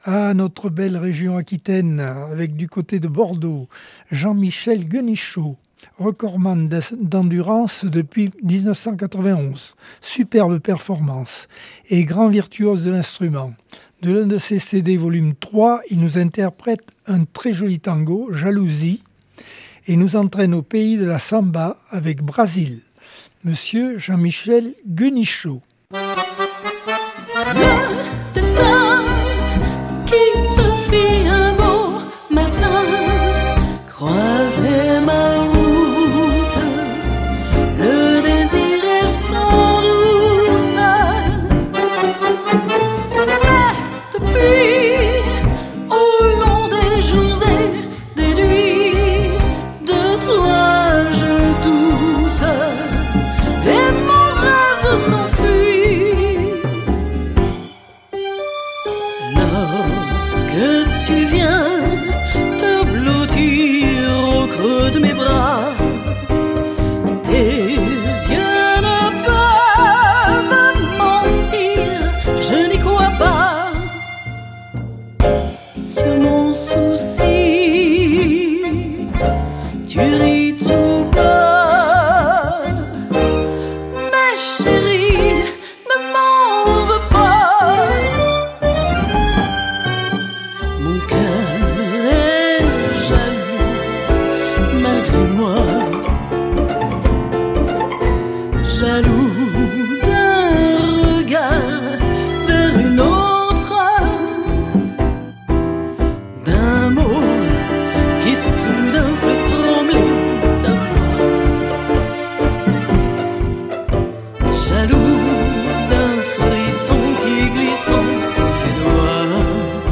Accordeon 2025 sem 51 bloc 4.